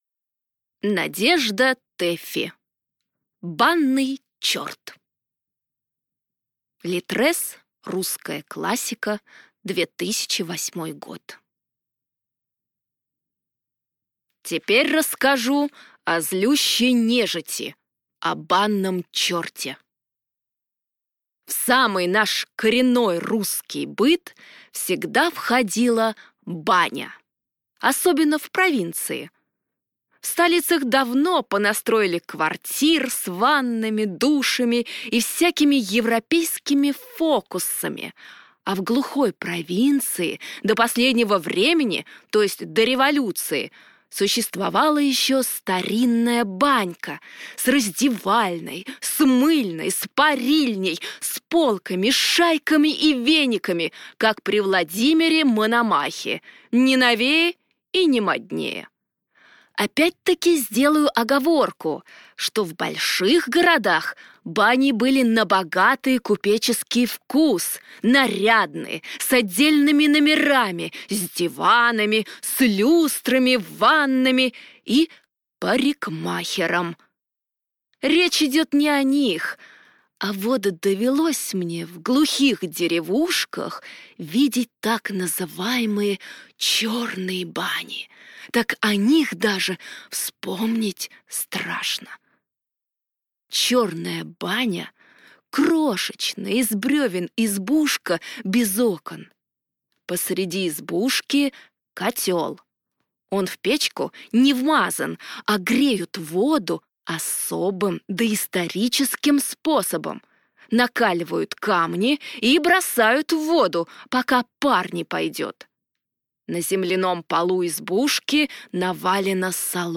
Аудиокнига Банный черт | Библиотека аудиокниг